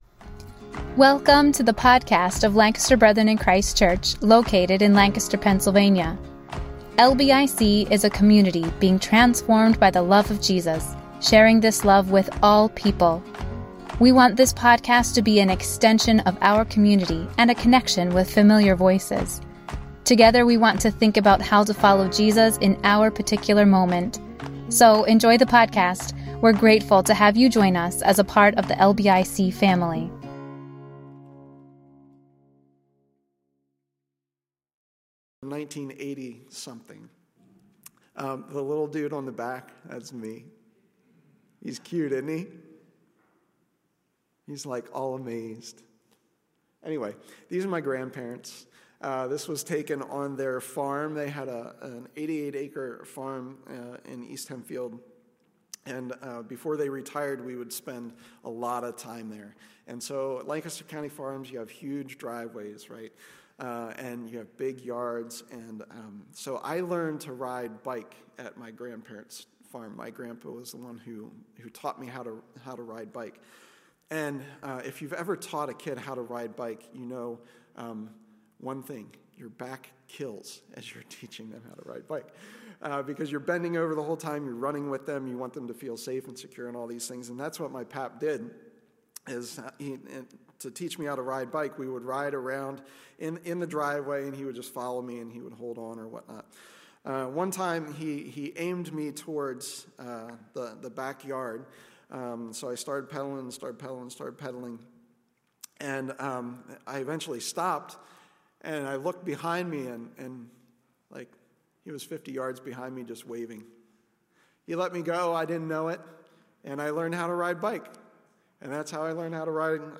7th Sunday of Easter: Waiting in the In-Between Service Message